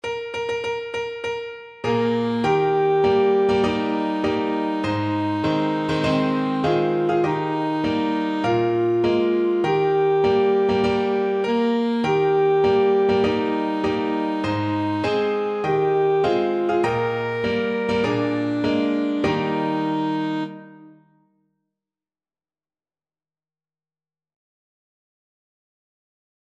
Alto Saxophone version
4/4 (View more 4/4 Music)
With gusto!
Bavarian Music for Alto Sax